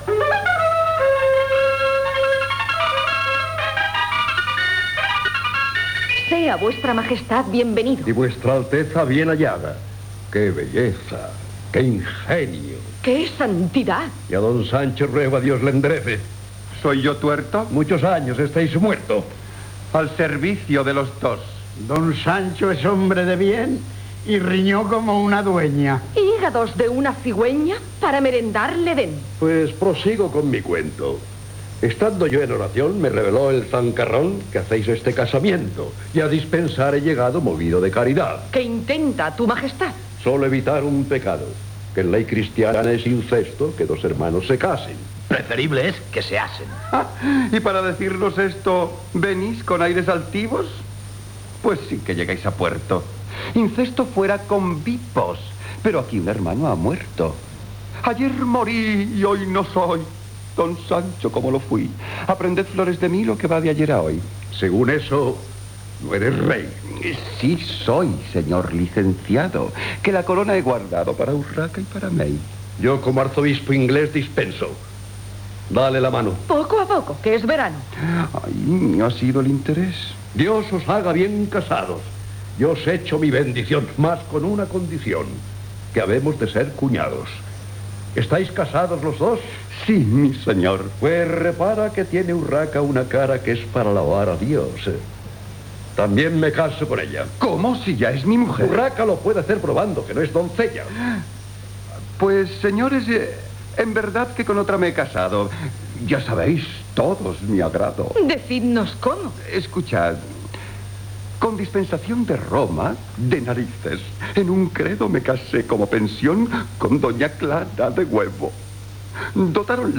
Adaptació radiofònica de la "Comedia famosa del hermano de su hermana", escrita per Francisco Bernardo de Quirós .Escena final i careta amb els crèdits del repartiment i de l'equiup de l'obra.
Ficció